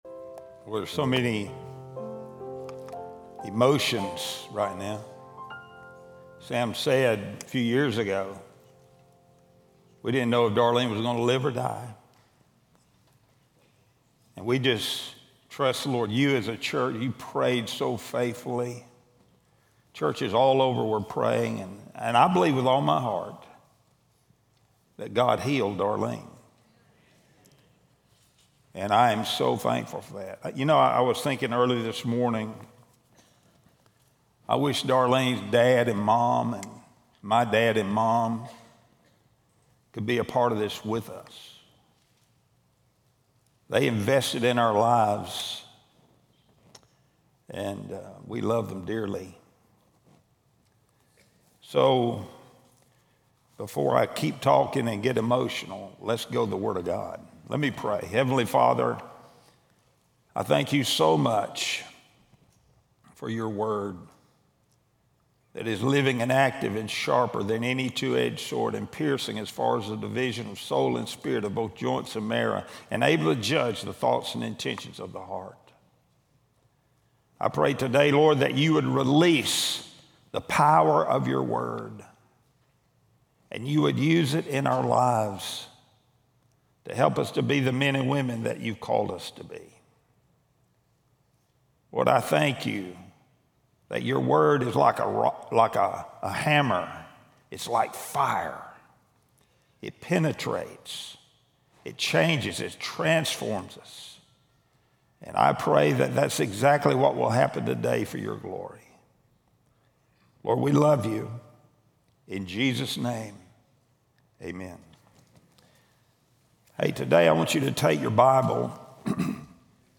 Sunday-Sermon-Audio-August-3-2025.mp3